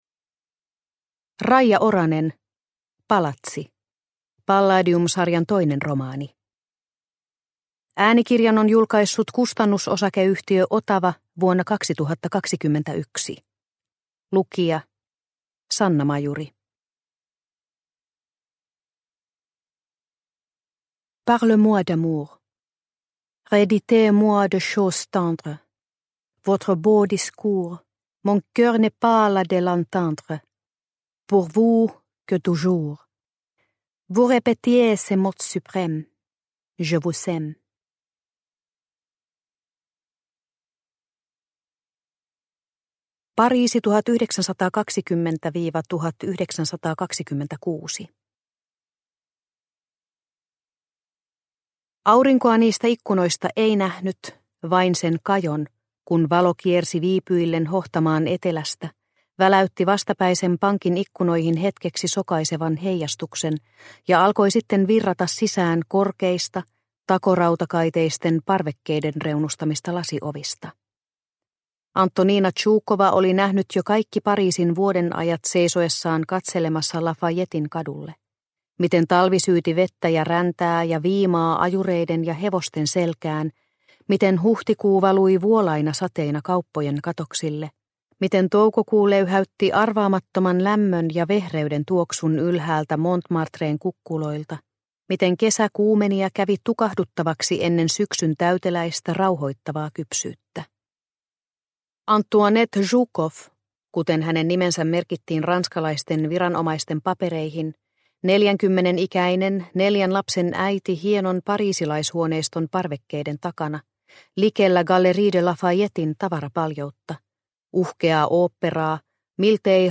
Palatsi – Ljudbok – Laddas ner